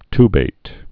(tbāt, ty-)